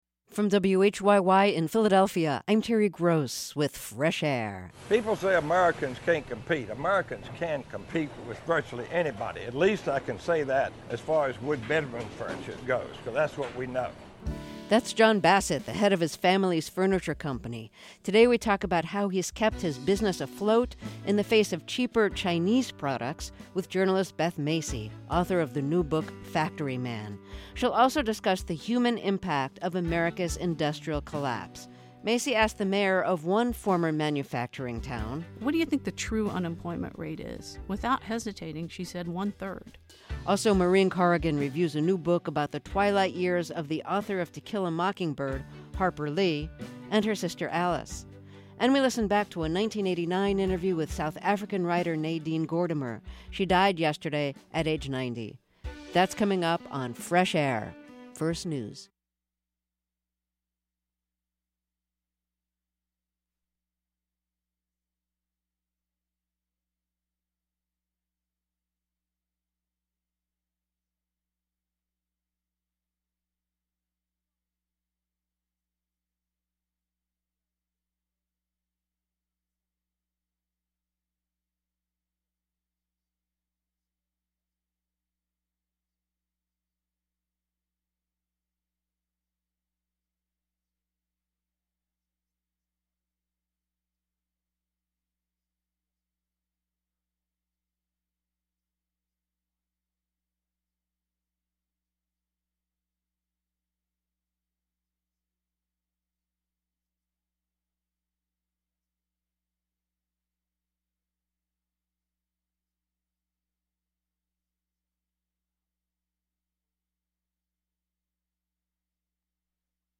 South Africa | Fresh Air Archive: Interviews with Terry Gross